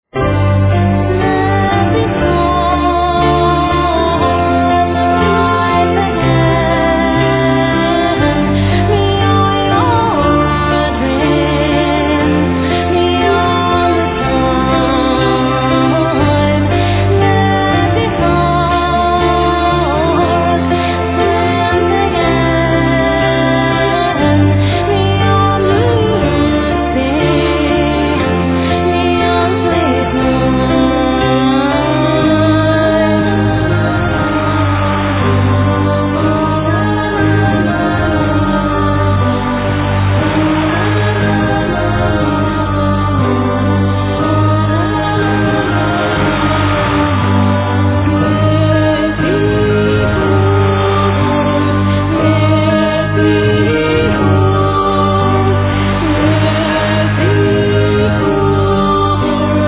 ゴシック・フォーク界のカリスマバンド名作復刻盤第２弾
女性ヴォーカル、ドラム＆ベースの男性
Voice, Hardy Gardy, Bells, Percussions, Flute
Bass, Darbuka, Drums, Voice
Bagpipes, Flute, Whistles
Acoustic guitars, Bouzouki
violin